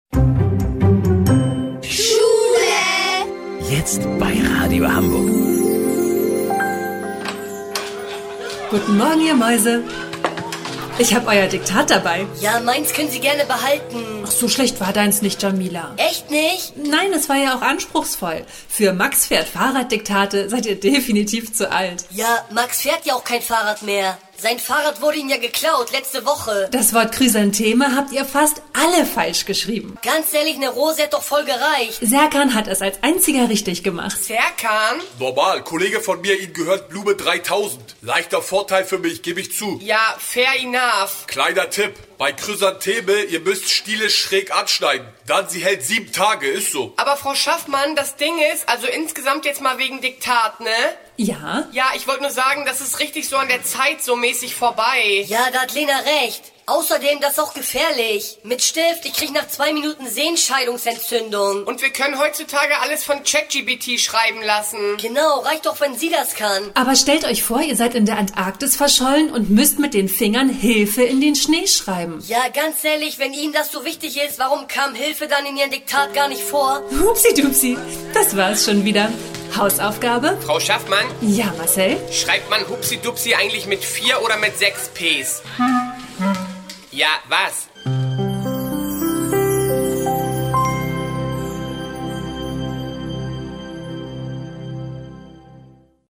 Comedy